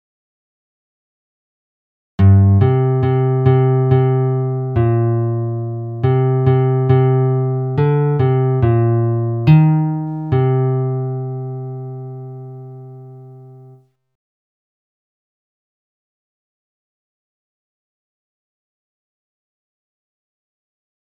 Key written in: C Major
Type: Other male
Each recording below is single part only.